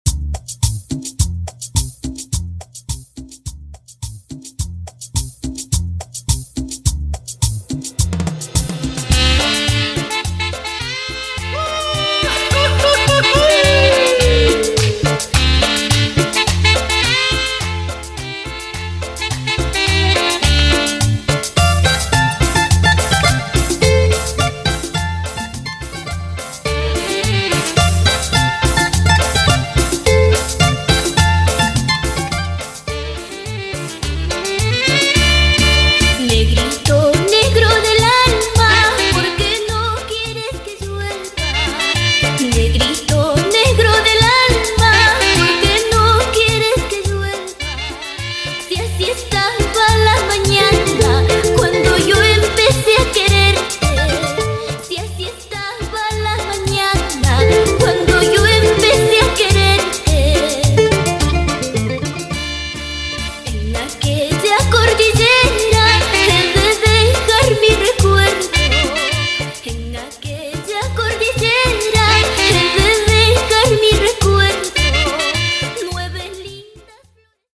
Latin music remixes and edits